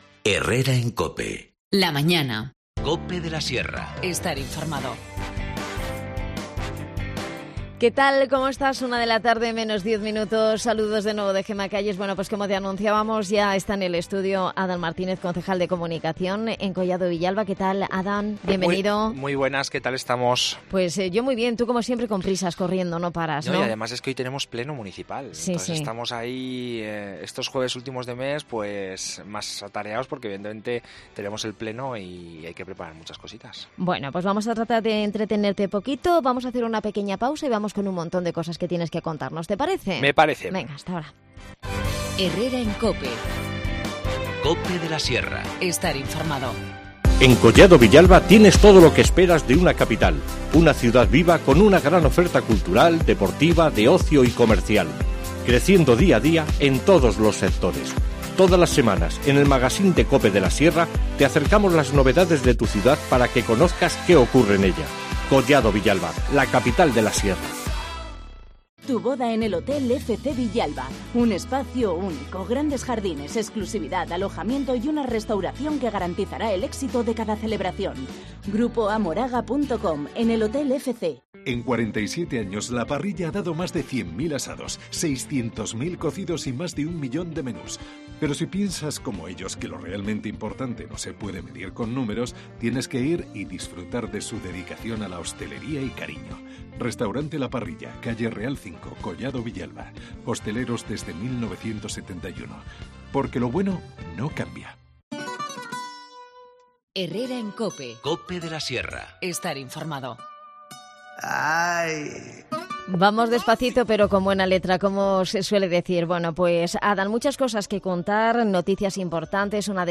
AUDIO: Hablamos con Adan Martínez concejal de Comunicación en Collado Villalba sobre toda la actualidad del municipio y las citas más inmediatas.